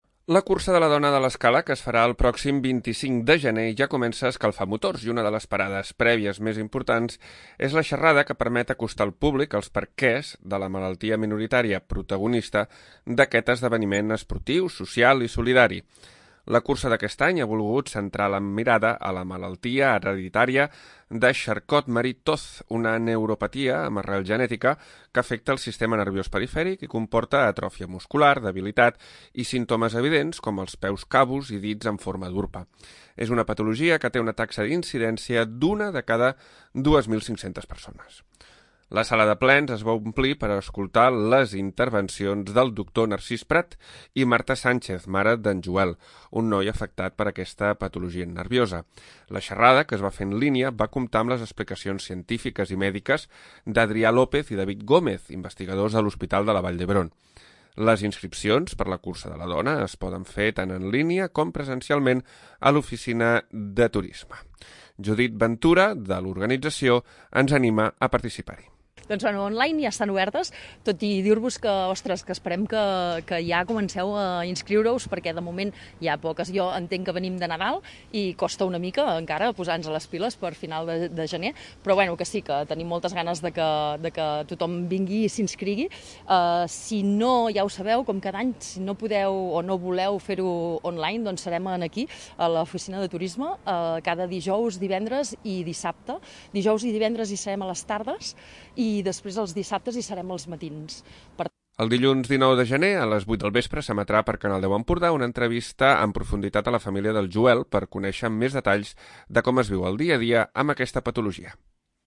La sala de plens de l'Ajuntament ha acollit la xerrada prèvia a la cursa de la dona. Una sessió amb molt de públic per entendre els perquès i els símptomes de la malaltia de Charcot Marie Tooth, una patologia hereditària del sistema nerviós perifèric.